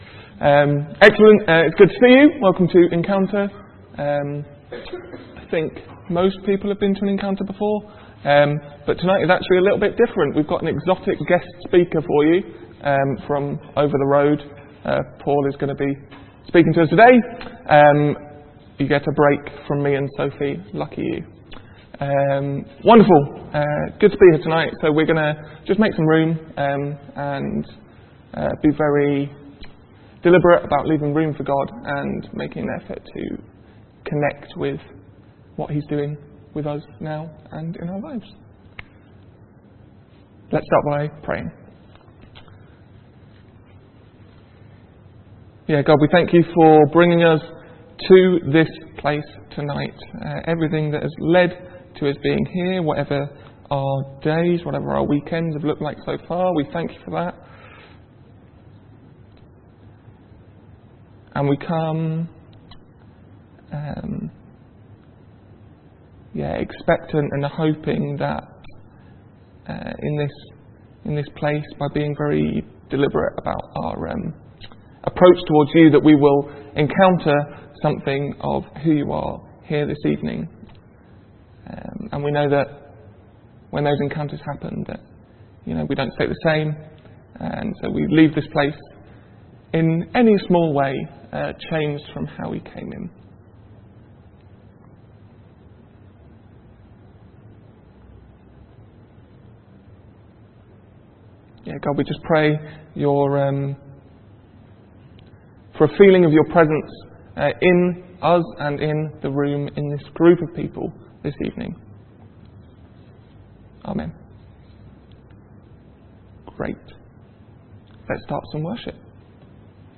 From Service: "6.30pm Service"